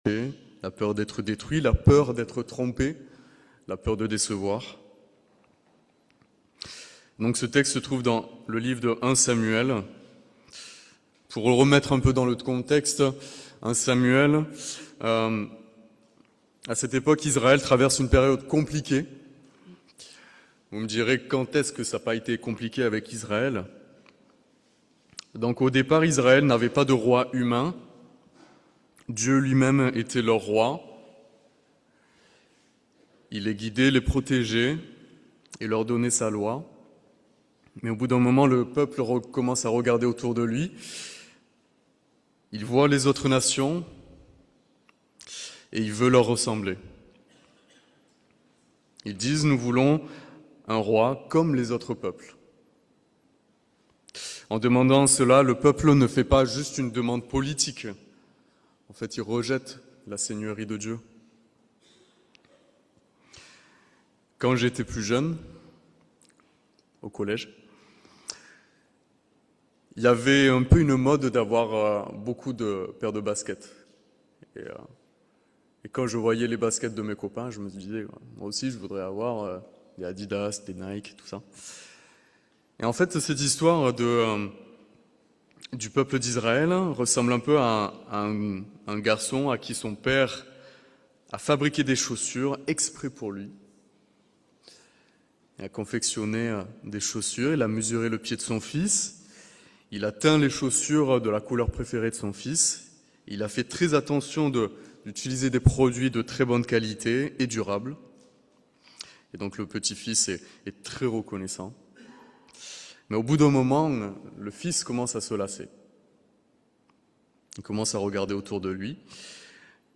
Message du 11 janvier 2026 - Eglise Réformée Evangelique Vauvert
Dimanche de l'Église persécutée